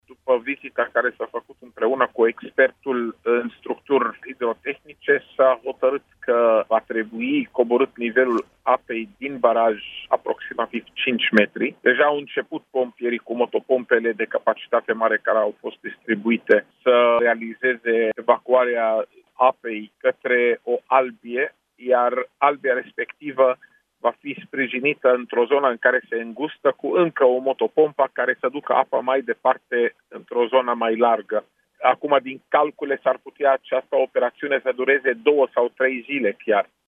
Nivelul barajului Tăul Mare, de la Roșia Montană, trebuie redus cel puțin cu 5 metri, astfel există riscul unei inundații, spune, la Europa FM, șeful Departamentului pentru Situații de Urgență – Raed Arafat.